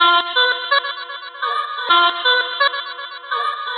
• chopped vocals ping pong house delayed.wav
chopped_vocals_ping_pong_house_delayed__GZ9.wav